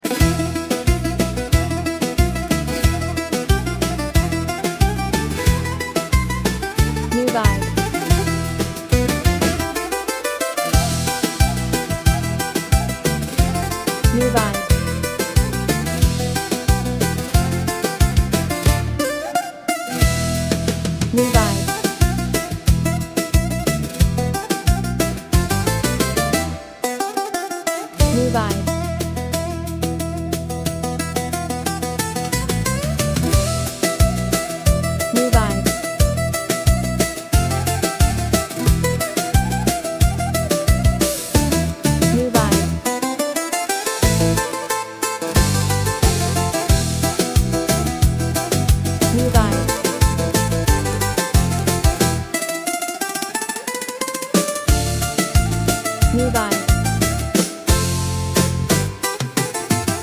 Genre: Greek Laiko